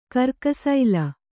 ♪ karkasaila